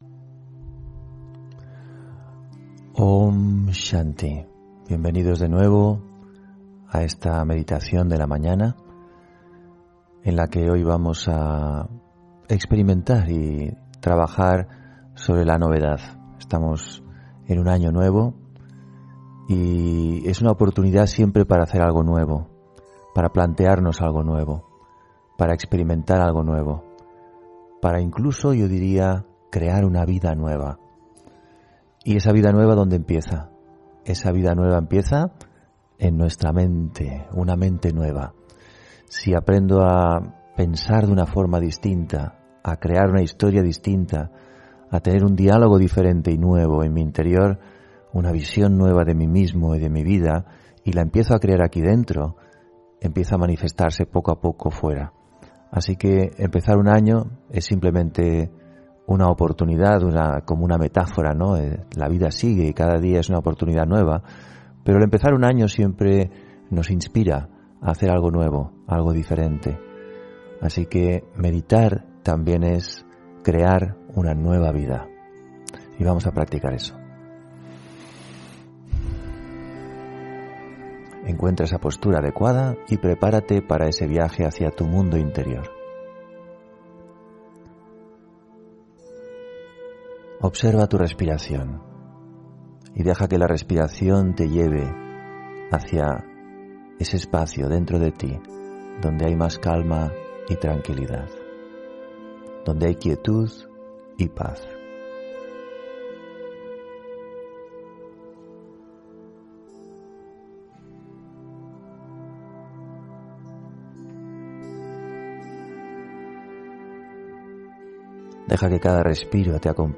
Meditación de la mañana: Una nueva vida